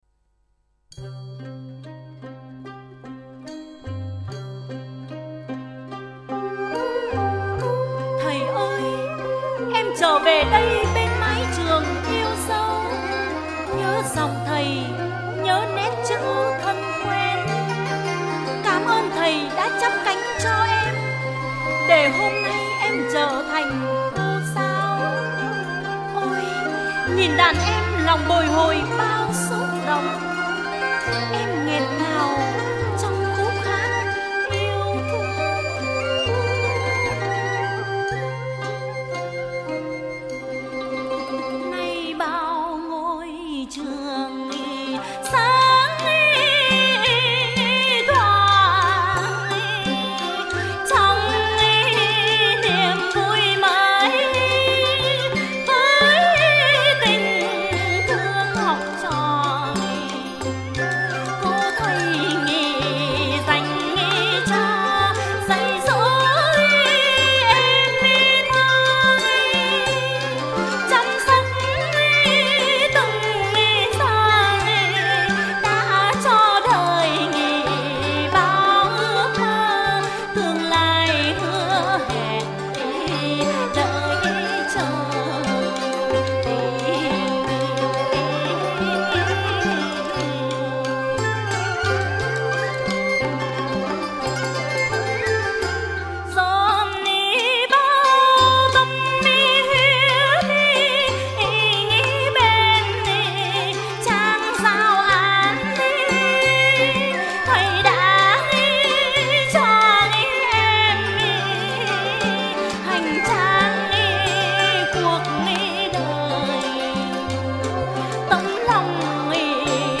thuộc thể loại Hát chèo cổ.